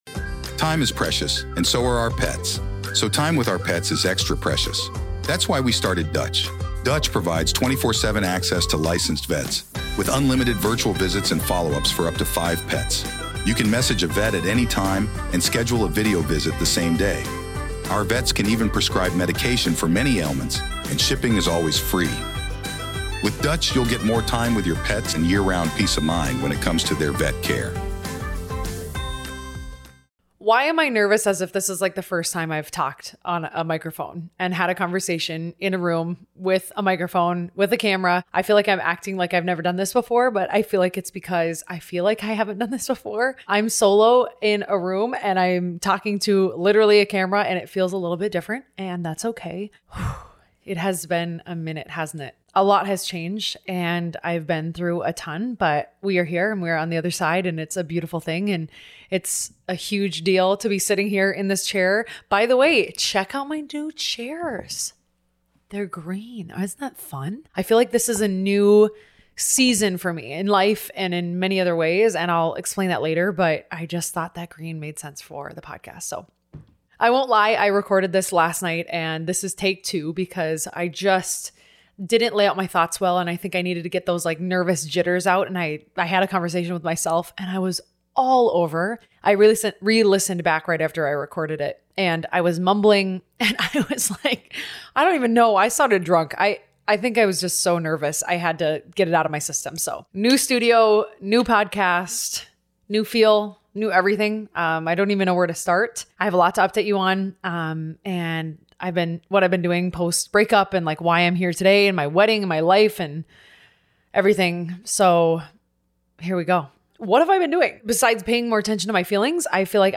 Bear with me as I get used to solo recording for the first time in a LONGGGG time.